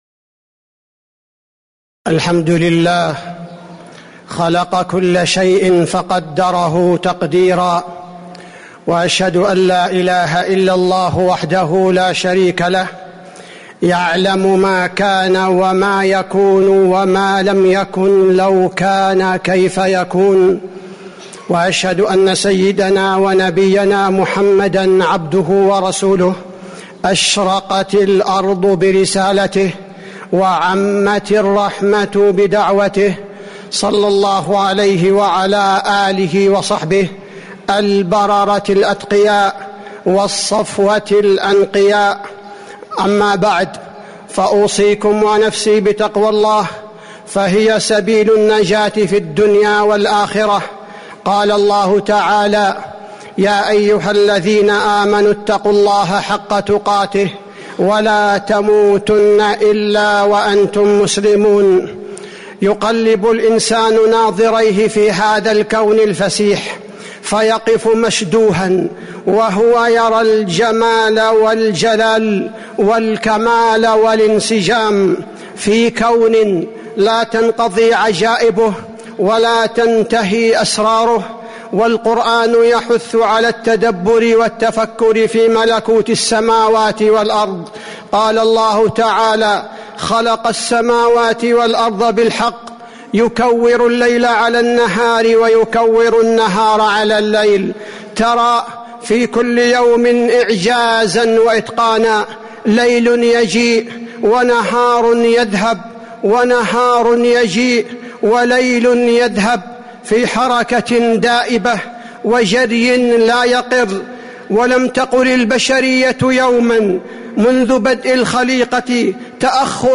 تاريخ النشر ١٤ ربيع الأول ١٤٤٥ هـ المكان: المسجد النبوي الشيخ: فضيلة الشيخ عبدالباري الثبيتي فضيلة الشيخ عبدالباري الثبيتي تأملات في بديع صنع الخالق